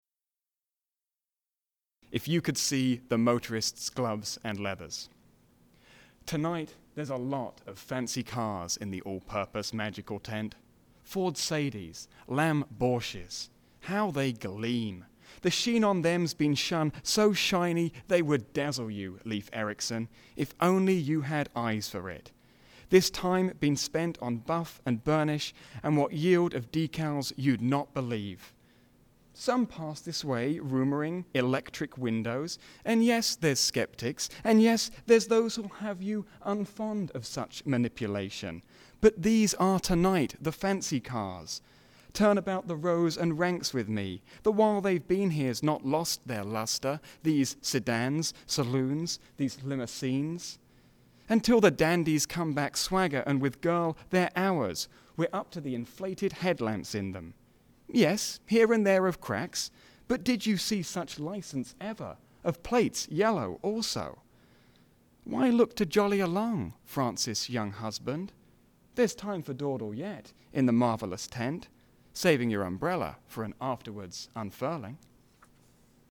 Gloves-Leathers-live.mp3